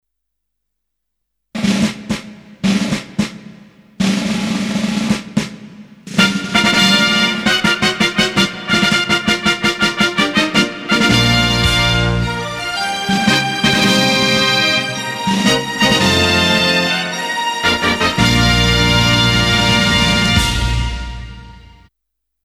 Звуки фанфар, награждения
Фанфары 21 века